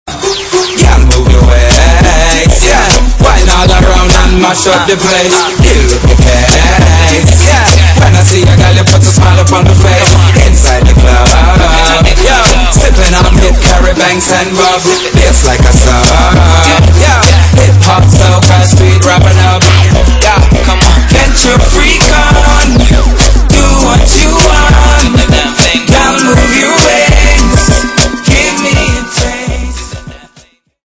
英文DJ DJ舞曲下载
分类: DJ铃声